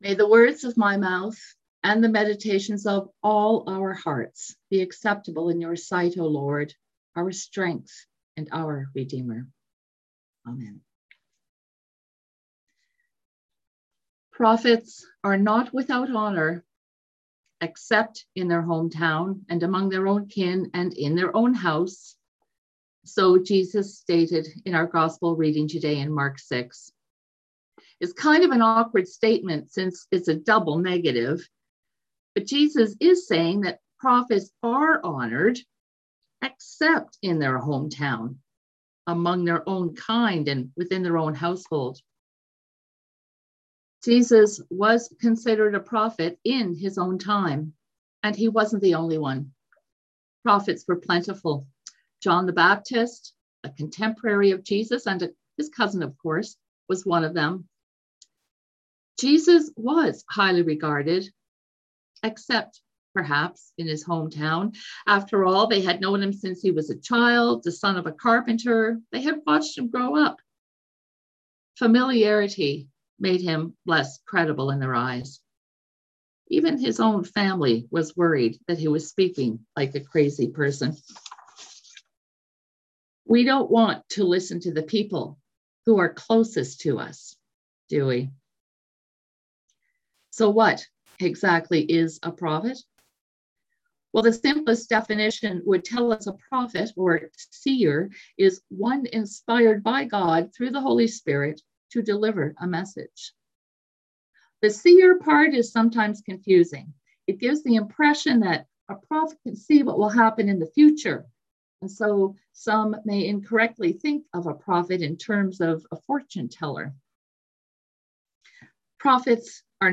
Sermons | St. George's Anglican Church